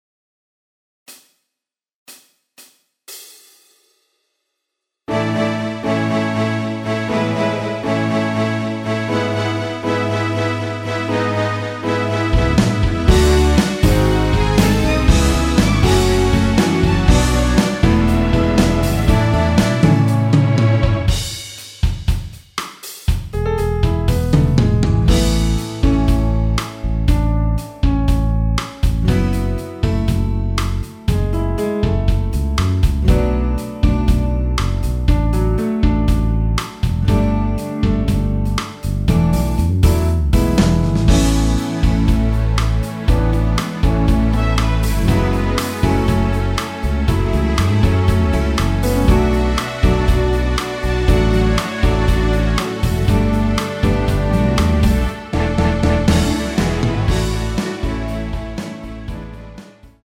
원키에서(-2)내린 MR입니다.
앞부분30초, 뒷부분30초씩 편집해서 올려 드리고 있습니다.